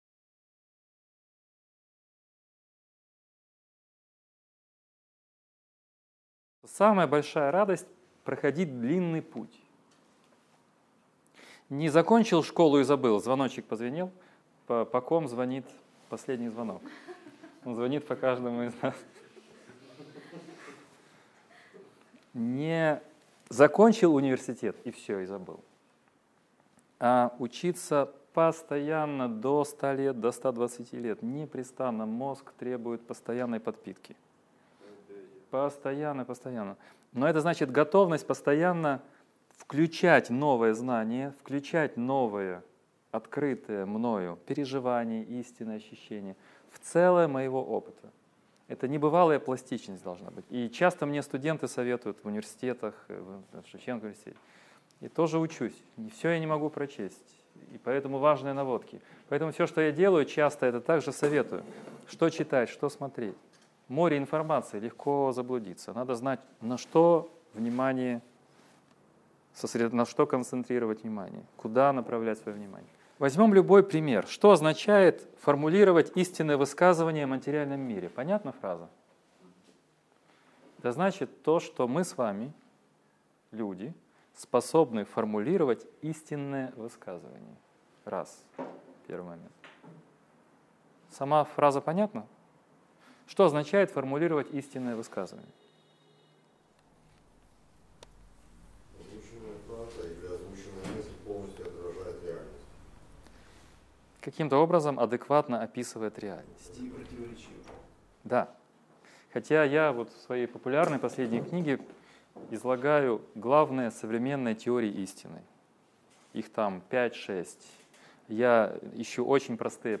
Аудиокнига Лекция 15. Можно ли объяснить сознание с помощью теории эволюции | Библиотека аудиокниг